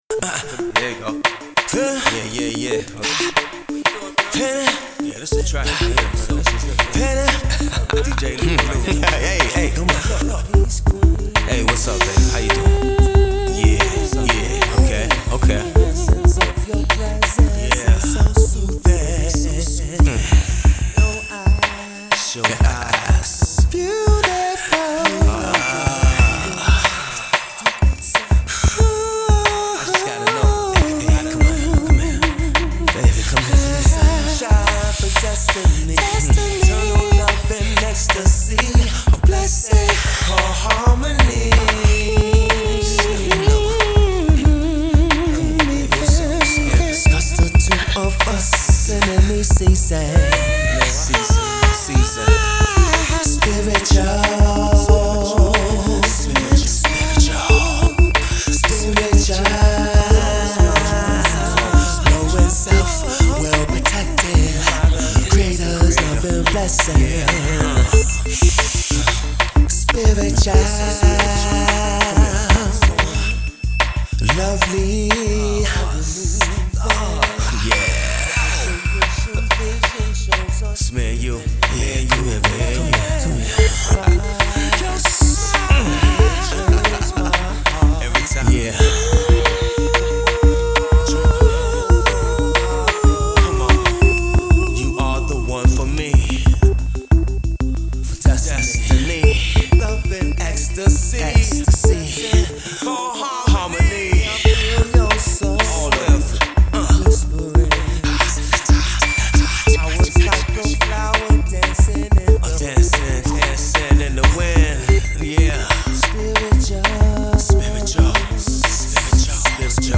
New Neo Soul R&B Heat for the ladies